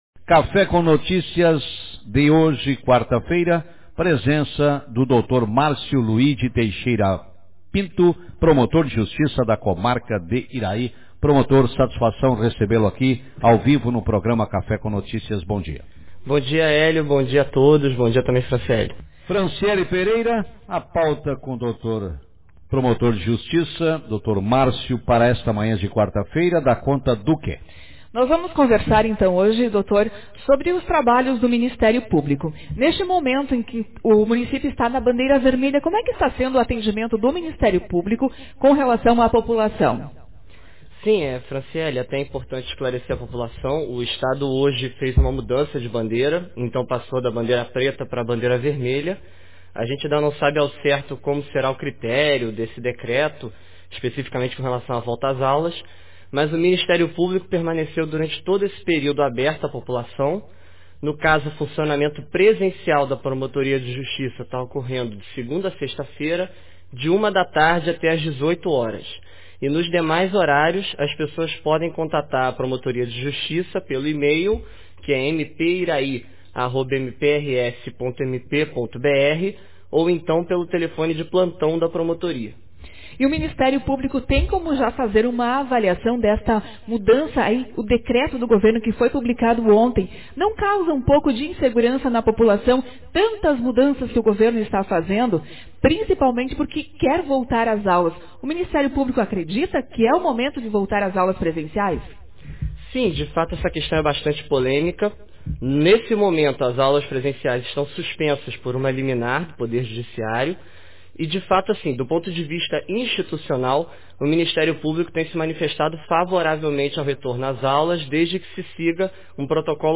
Promotor de Justiça destaca o trabalho do Ministério Público no município de Iraí Autor: Rádio Marabá 28/04/2021 0 Comentários Manchete No programa Café com Notícias desta quarta-feira, o entrevistado foi o promotor de Justiça da Comarca de Iraí, Dr. Márcio Luigi Teixeira Pinto, que falou sobre o trabalho do Ministério Público no município. Entre os pontos abordados está o decreto publicado pelo governo do Rio Grande do Sul, ontem, terça-feira, que coloca todo o Estado sob bandeira vermelha, permitindo a retomada das aulas presenciais. Confira a entrevista